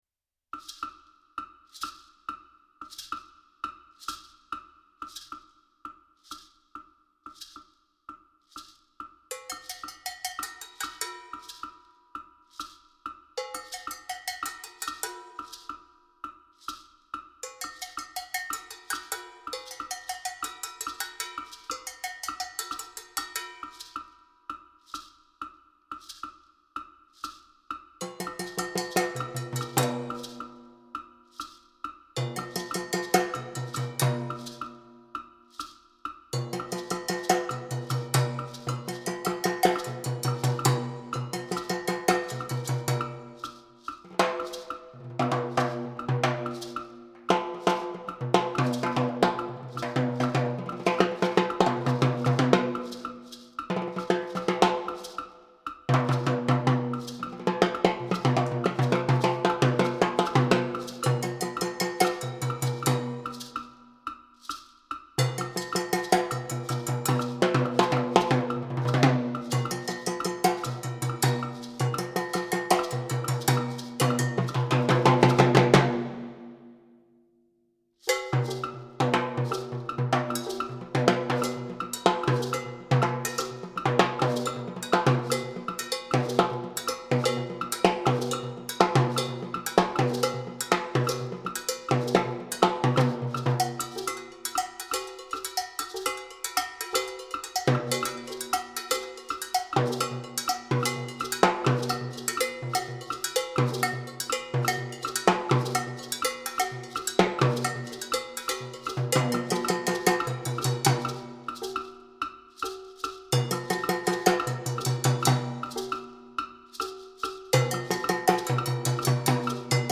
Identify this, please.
Voicing: Timbales